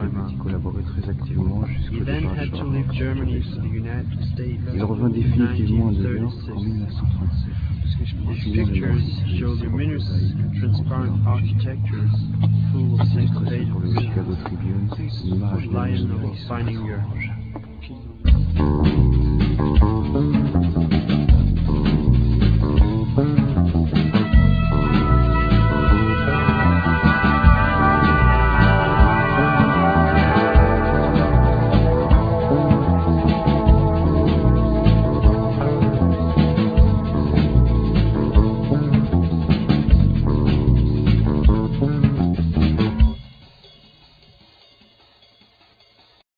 Guitar
Drums,Percussions
Contrabass
Saxophones,Synthesizer
Vocal
Bass